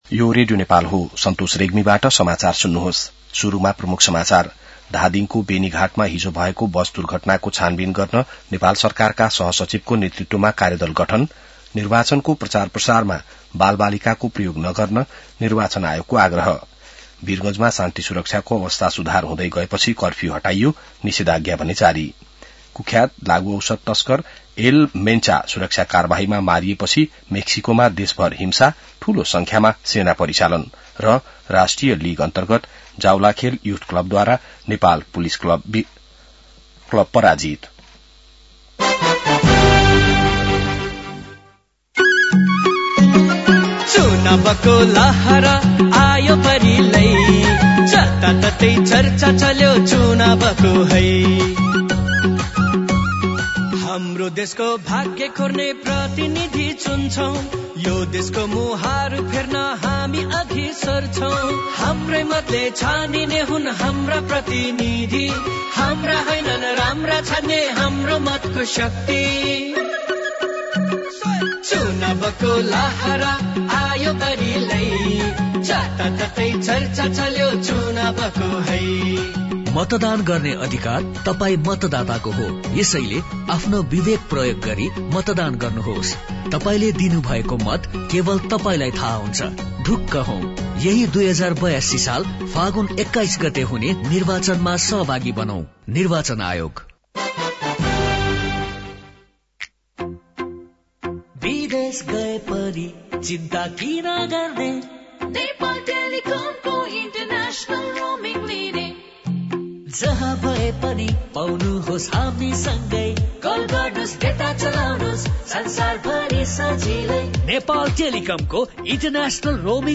बिहान ७ बजेको नेपाली समाचार : १२ फागुन , २०८२